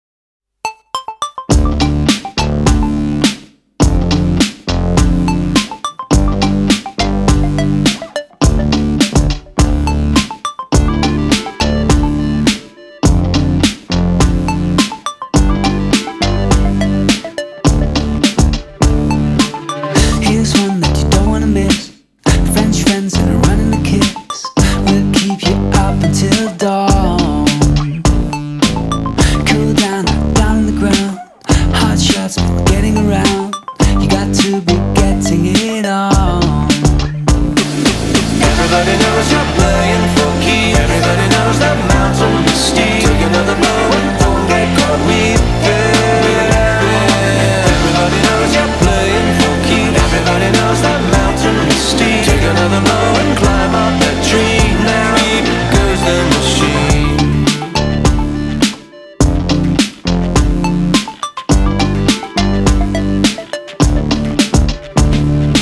• Качество: 224, Stereo
мужской вокал
красивые
dance
спокойные
vocal